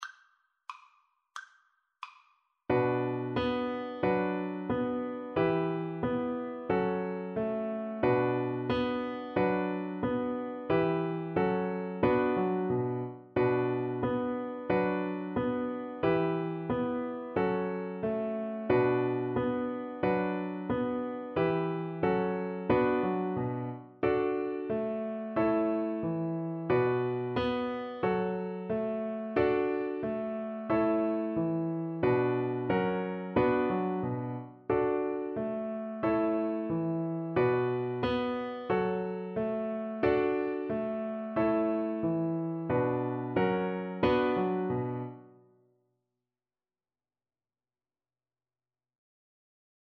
Violin
Traditional Music of unknown author.
2/4 (View more 2/4 Music)
Andante = 90
B minor (Sounding Pitch) (View more B minor Music for Violin )